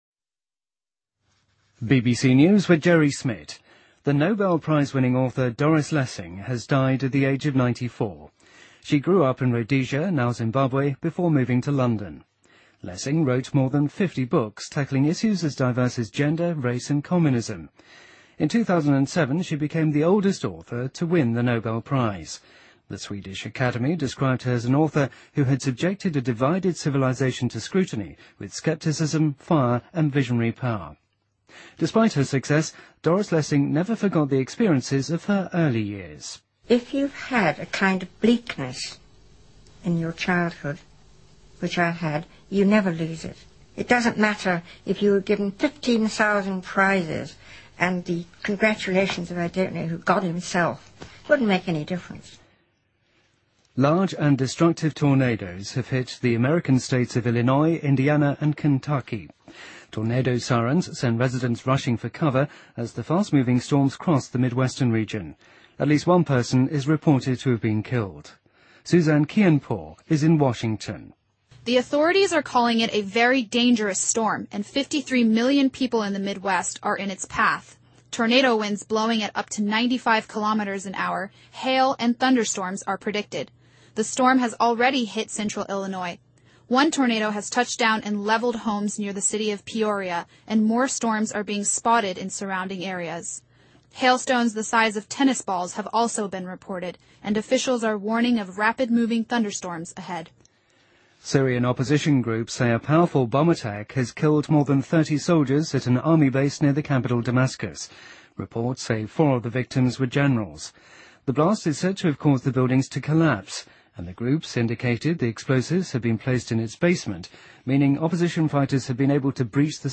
BBC news,俄罗斯官员称一架客机在喀山市坠毁，导致50人丧生